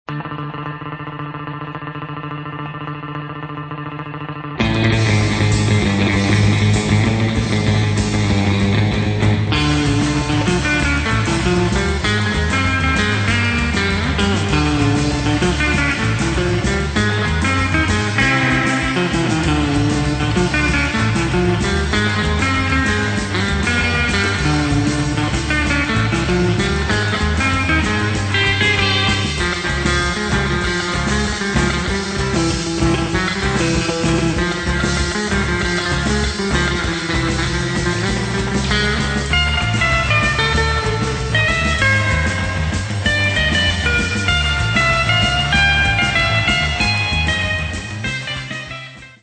exciting fast instr.